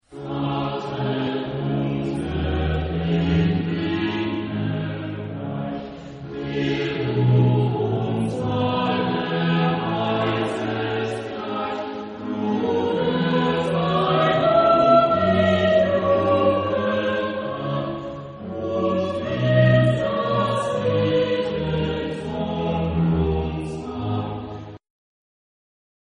Género/Estilo/Forma: Coral ; Sagrado
Tipo de formación coral: SATB  (4 voces Coro mixto )
Instrumentos: Organo (ad lib)